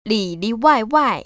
[lĭ‧liwàiwài] 리리와이와이